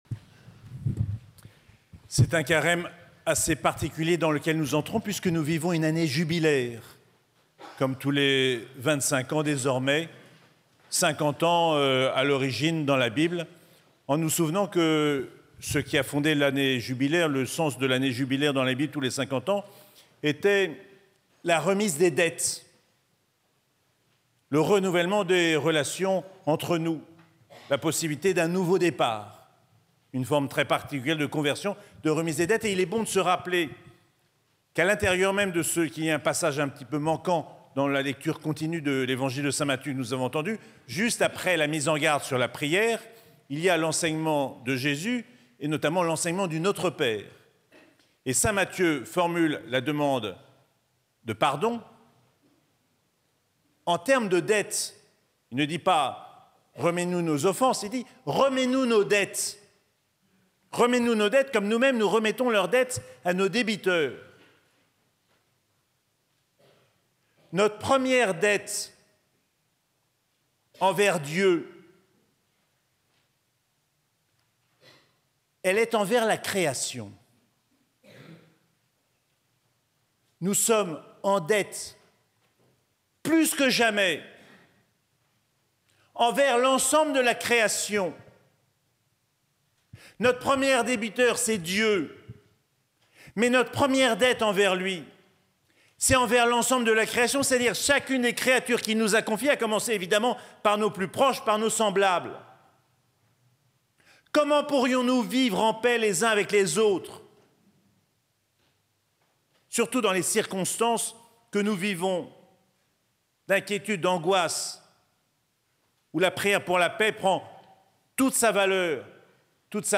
Mercredi des Cendres - 5 mars 2025